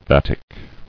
[vat·ic]